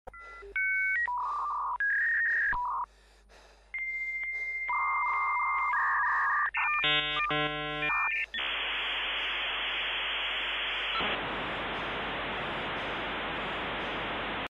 GROWING UP: Nothing like the dial up sounds